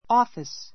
ɔ́ːfis